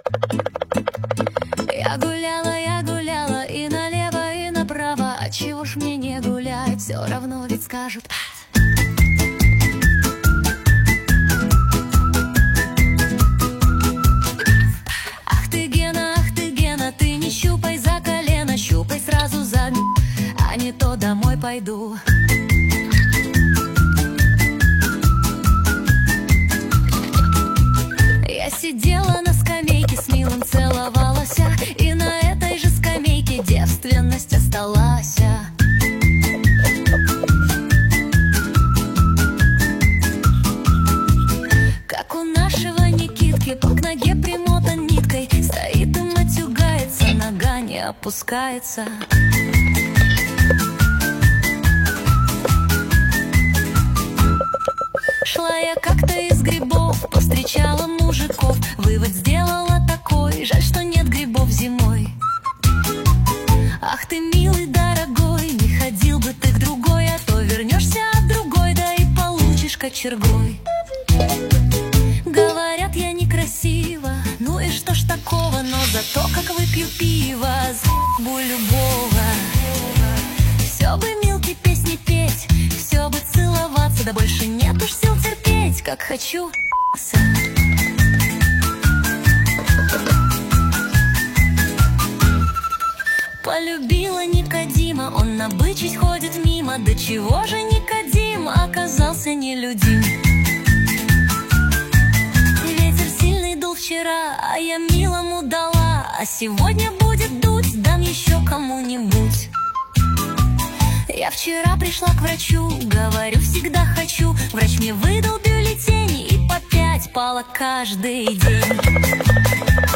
Качество: 320 kbps, stereo
Нейросеть Песни 2025